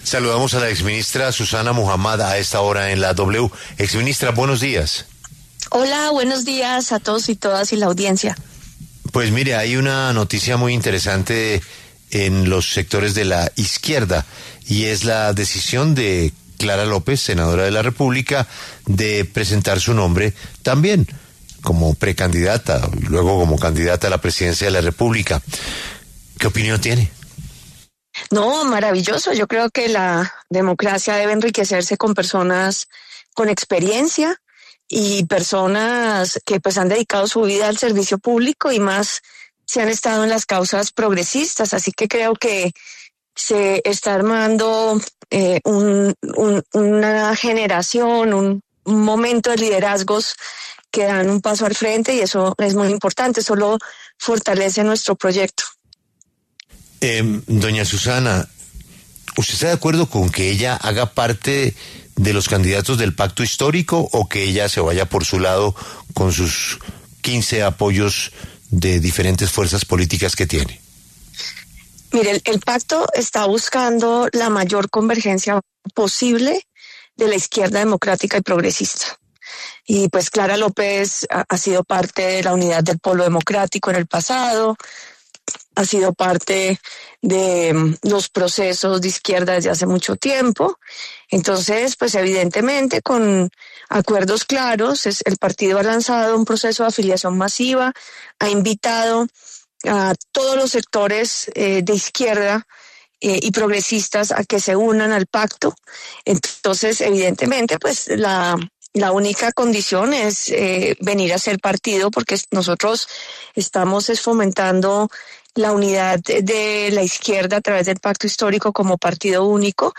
Por esta razón, en los micrófonos de La W, con Julio Sánchez Cristo, habló otra precandidata de ese sector, la exministra de Ambiente Susana Muhamad.